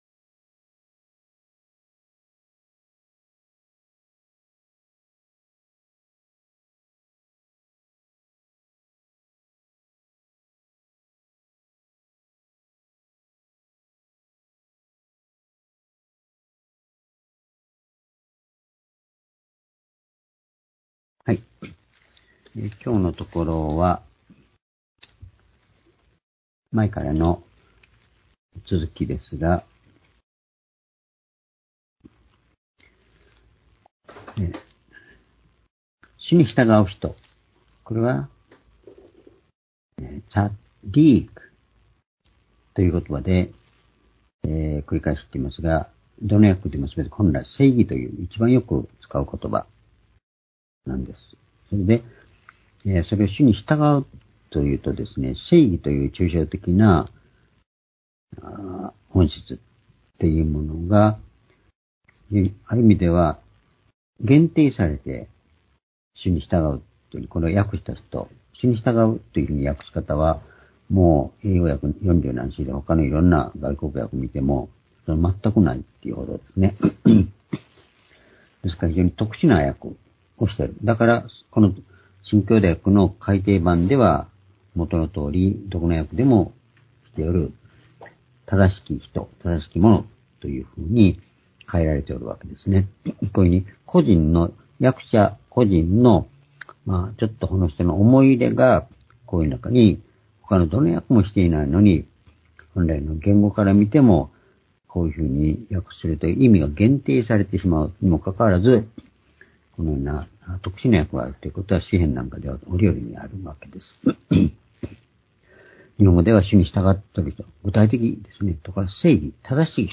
（主日・夕拝）礼拝日時 2023年10月１７日（夕拝） 聖書講話箇所 「平和（シャーロームの人）の人の未来」 詩篇３７の３０～４０ ※視聴できない場合は をクリックしてください。